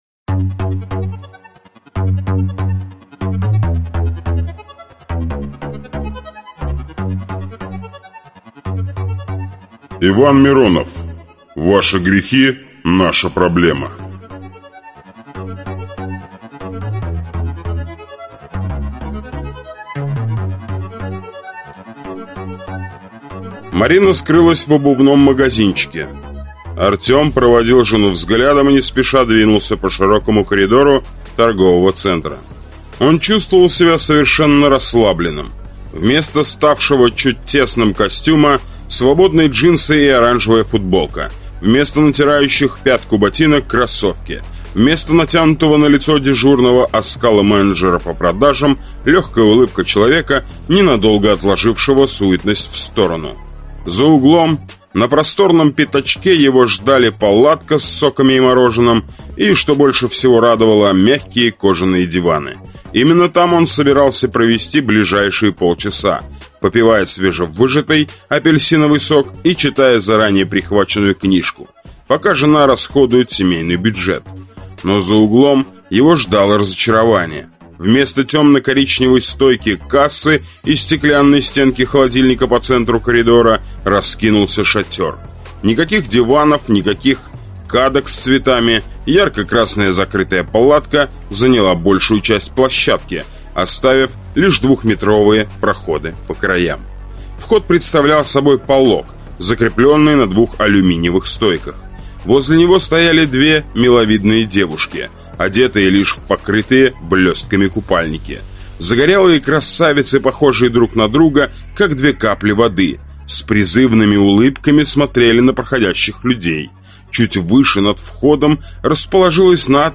Аудиокнига Рубикон | Библиотека аудиокниг